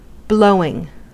Ääntäminen
Vaihtoehtoiset kirjoitusmuodot (rikkinäinen englanti) blowin' Ääntäminen US : IPA : [blɔʊwɪŋ/] Tuntematon aksentti: IPA : /bləʊwɪŋ/ Haettu sana löytyi näillä lähdekielillä: englanti Käännöksiä ei löytynyt valitulle kohdekielelle.